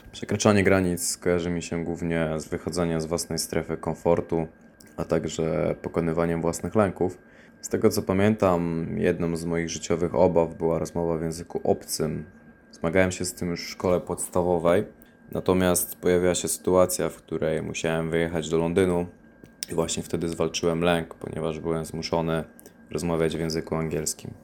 Zapytaliśmy studentów, co według nich oznacza “przekraczanie granic” i czy mieli okazję podjąć się tego w życiu: